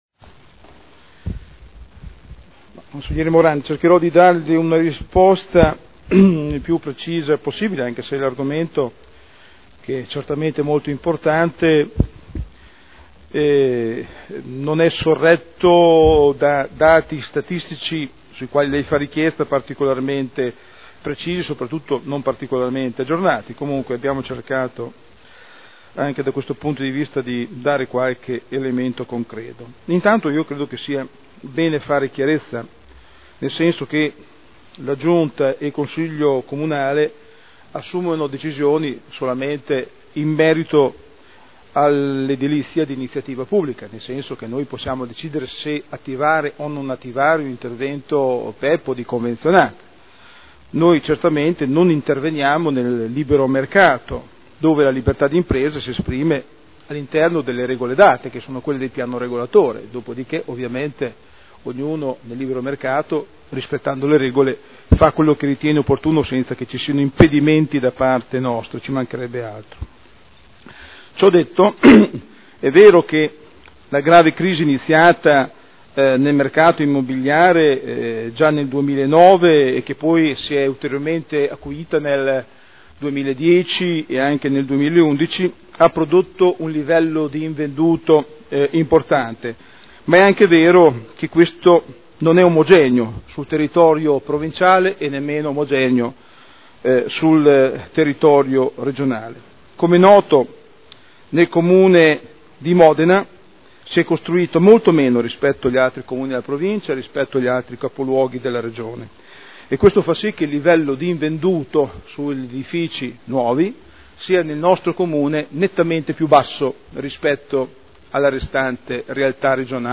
Daniele Sitta — Sito Audio Consiglio Comunale